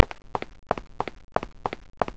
footsteps/n96.wav · s3prl/Nonspeech at main
Nonspeech / footsteps /n96.wav